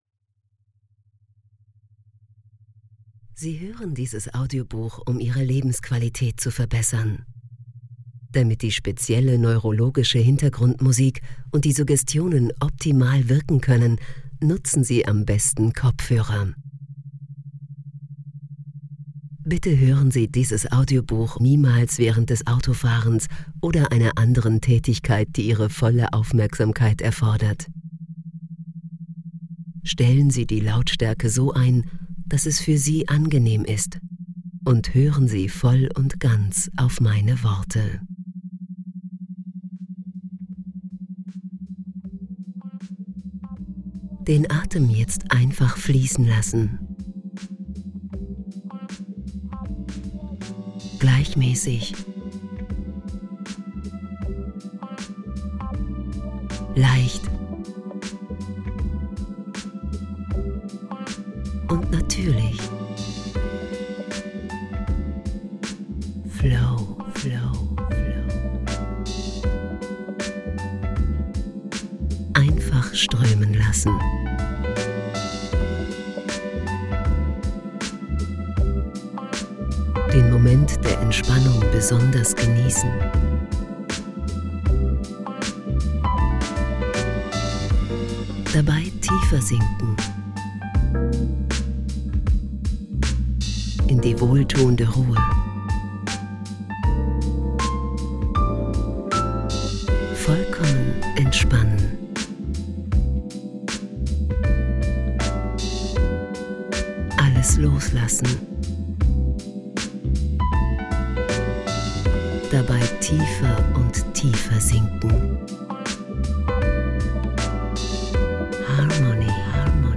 Relax Lounge Chillout Music